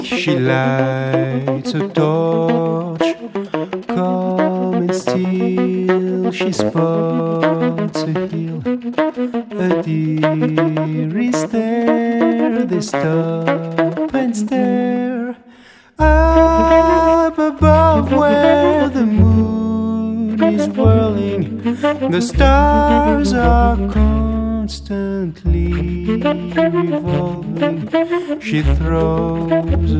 saxo et clar.